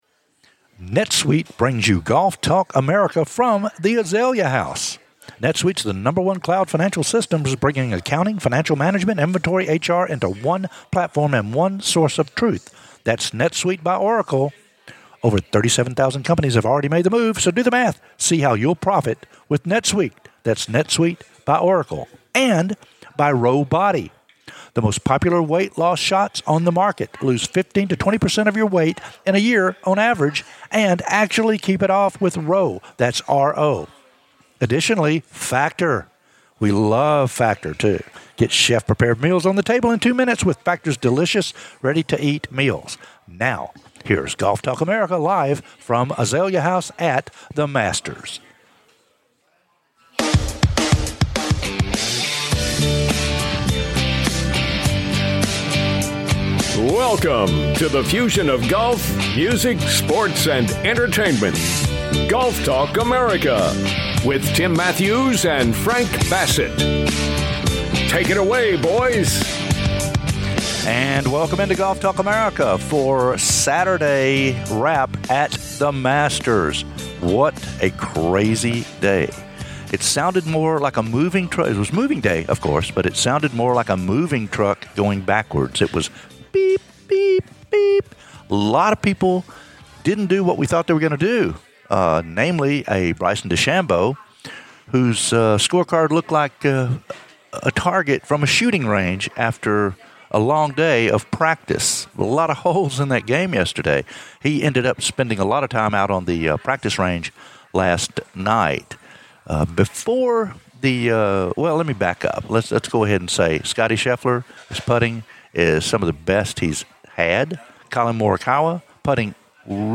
"LIVE" SATURDAY AT "THE MASTERS"